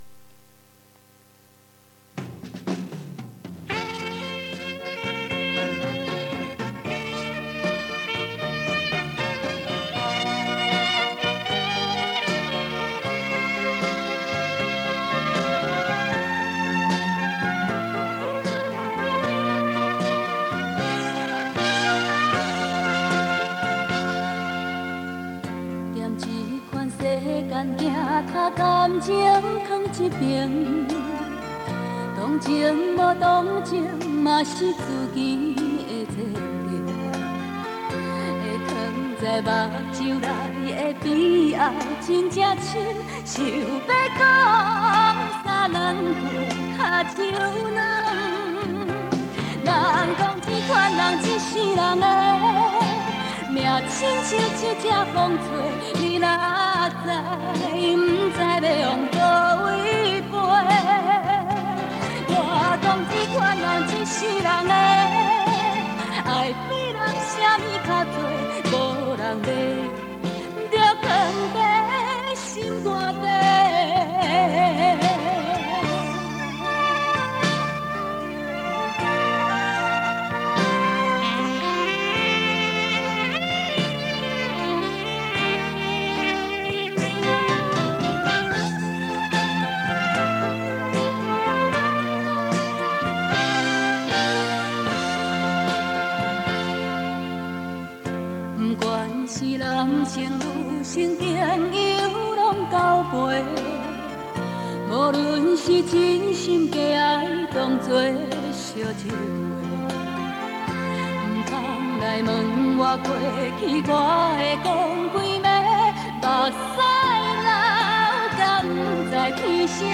磁带数字化：2022-08-27
90分钟系列 台语畅销金曲